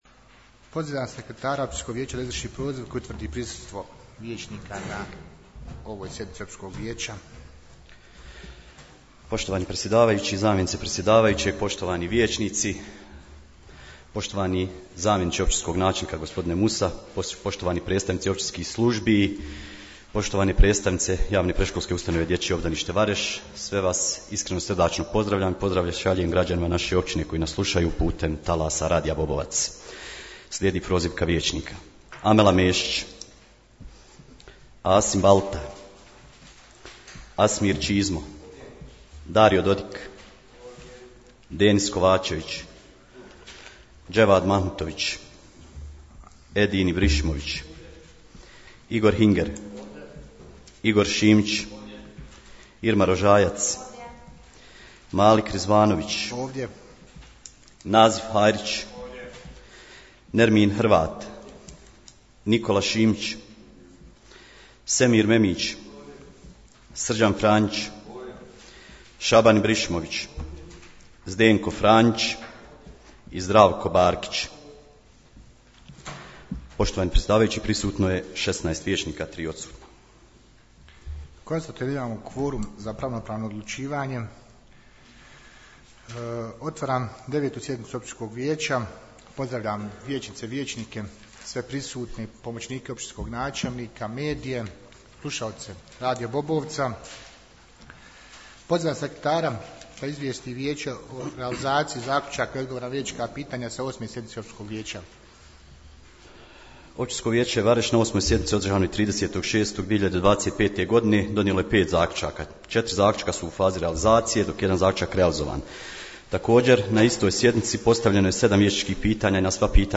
U petak, 25.07.2025. godine održana je 9. sjednica Općinskog vijeća Vareš, poslušajte tonski zapis ....